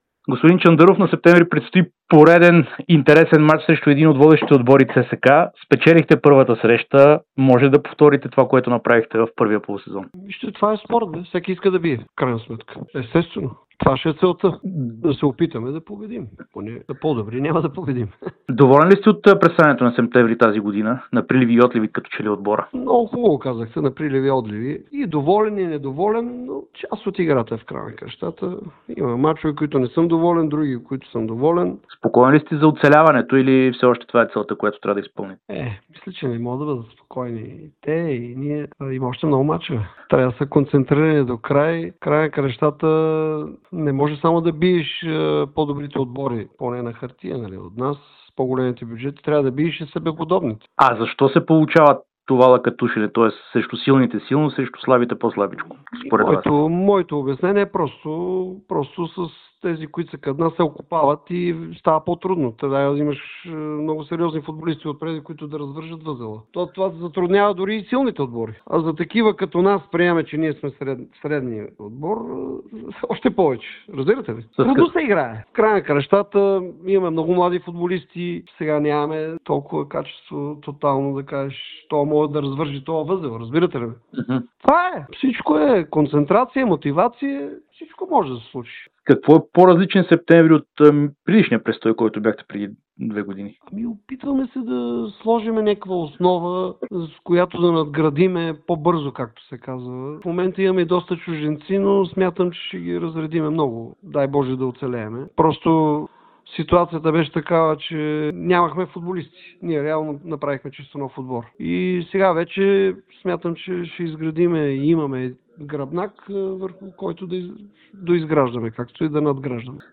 ексклузивно интервю пред Дарик радио и dsport